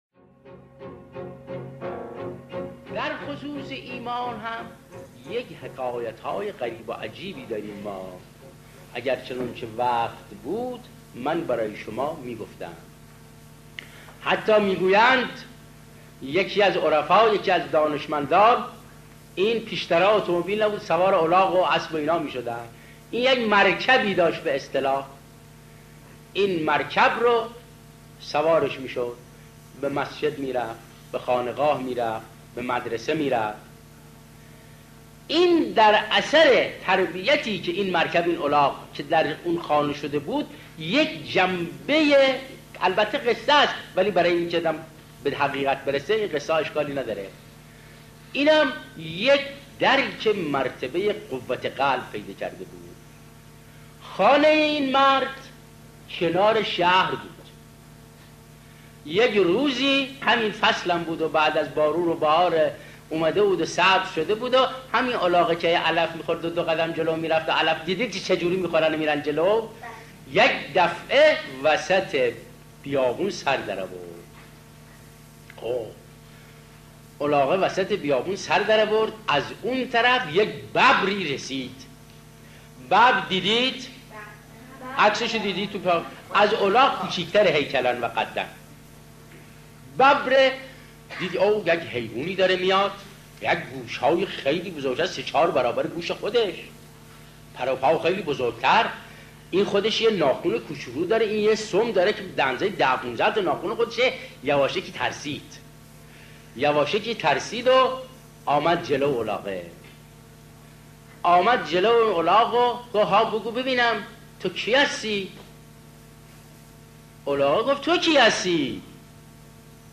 دانلود قصه «ایمان» که فضل الله مهتدی (صبحی)۴.۲۵ مگابایت